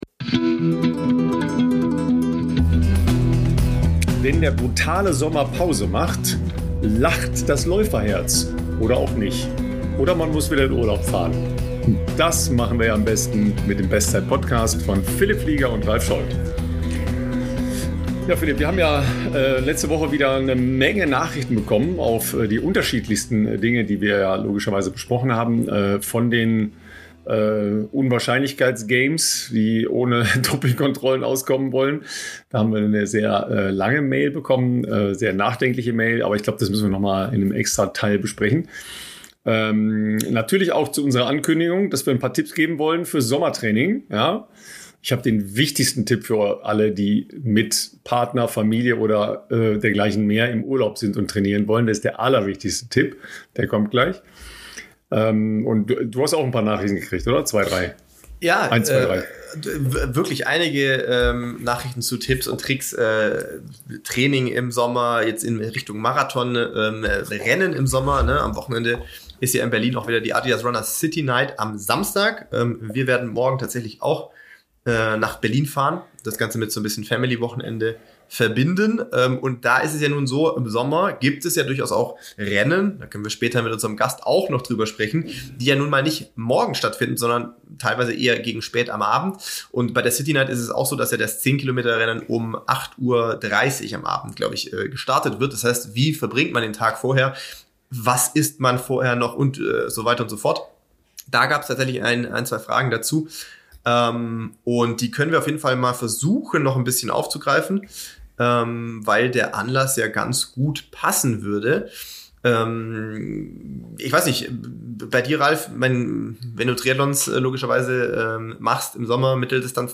Wenn sich der Marathonprofi und der Journalist unterhalten geht es also natürlich um’s Laufen und das aktuelle Geschehen in der Ausdauersportwelt. Es geht aber auch um Behind-the-Scenes-Einblicke in ihr tägliches Leben für den Sport, ihre unterschiedlichen Erlebniswelten und die damit verbundenen Blickwinkel.